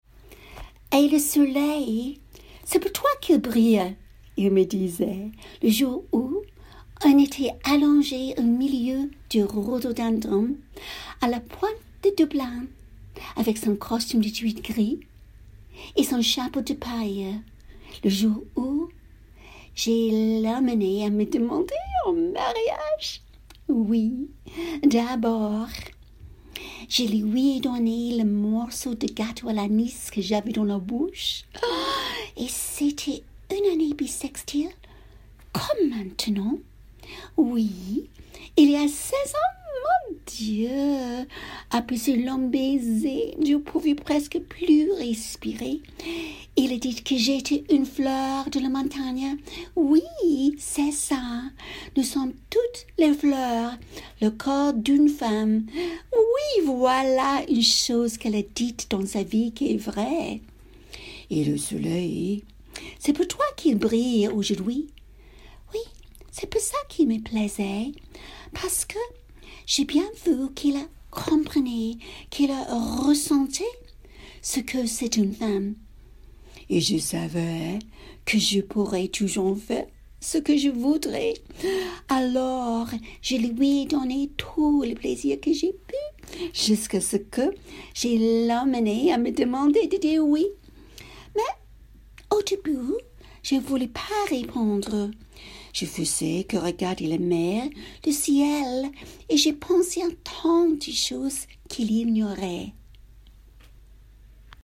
Texte de James Joyce, en français à la cadence irlandaise
Voix off